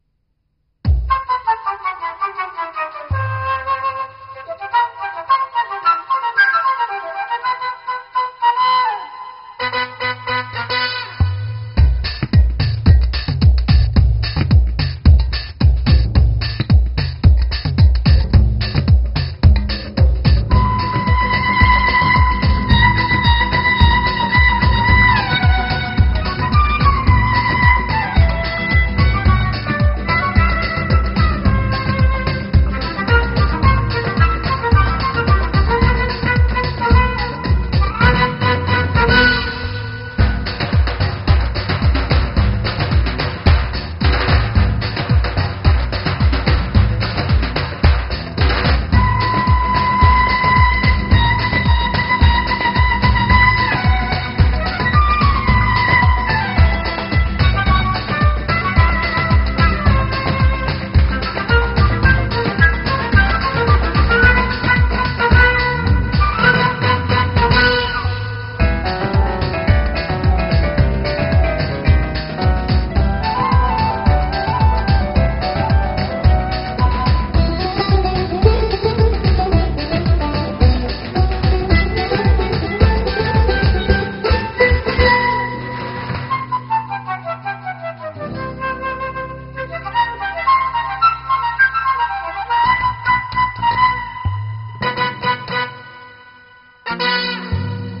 تیراژ بی کلام شروع